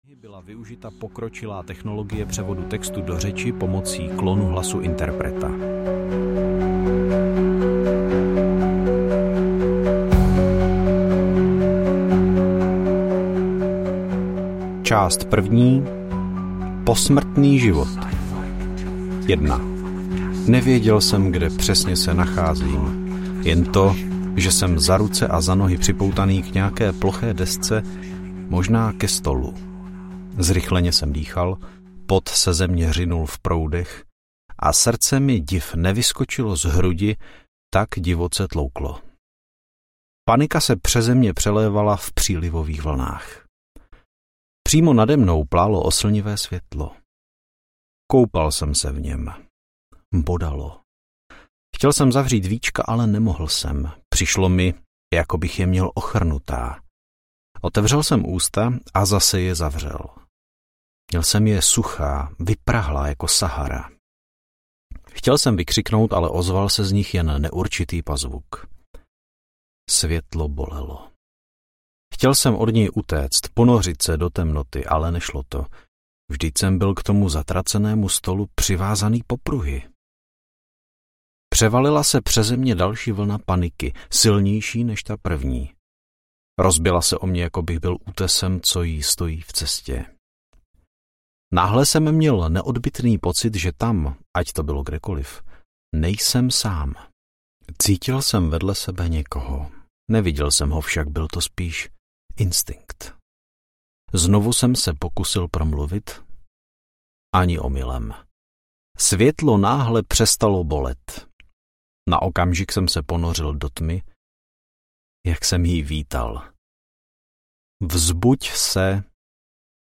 Rakev audiokniha
Ukázka z knihy